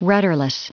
Prononciation du mot : rudderless